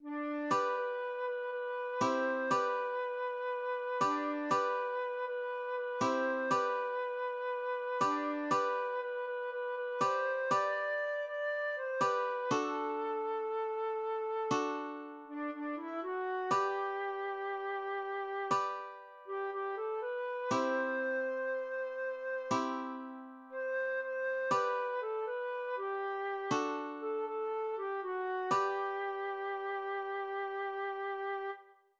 Seemanslied aus dem Maraskankrieg